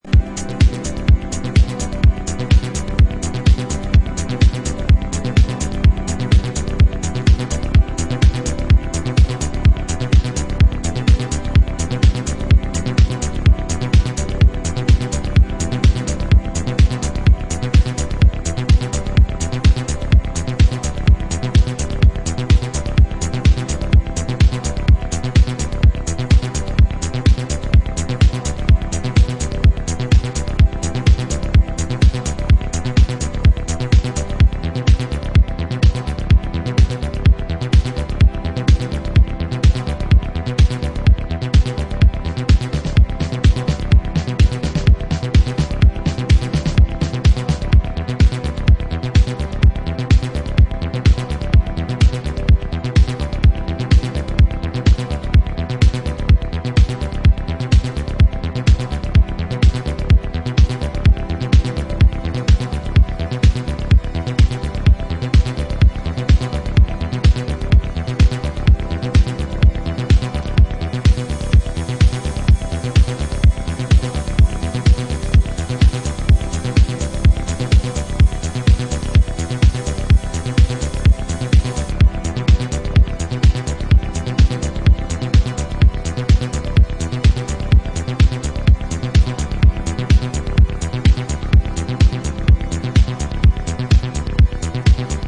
シカゴ・ ハウス注目の次世代気鋭プロデューサー